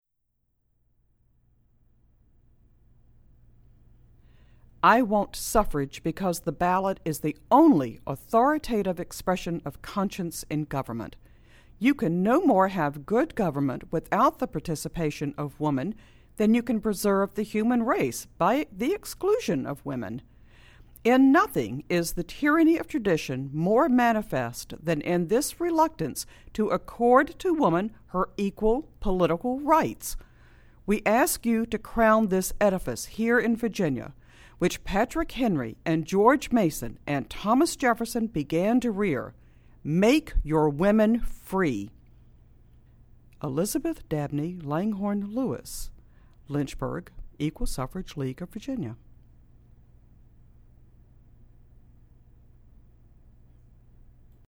Audio clip: excerpt from Elizabeth Lewis's January 19, 1912, speech to a committee of the Virginia House of Delegates printed in the Equal Suffrage League's pamphlet, Make Your Women Free, read by Library of Virginia staff.